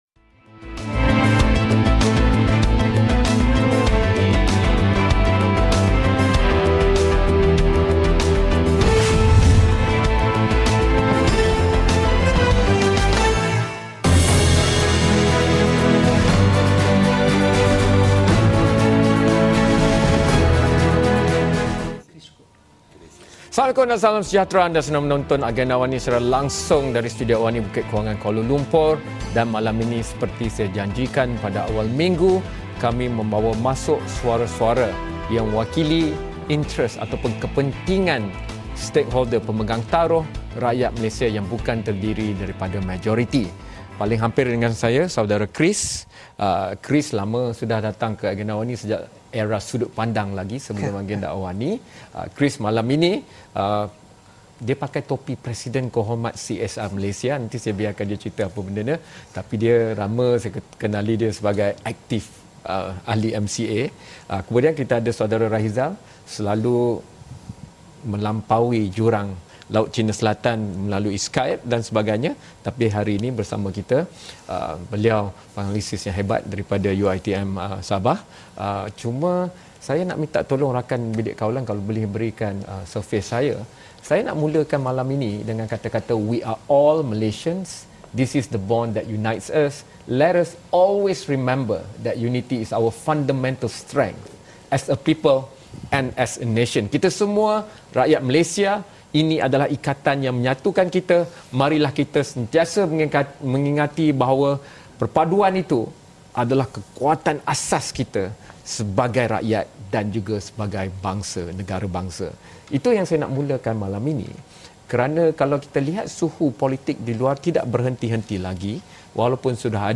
Diskusi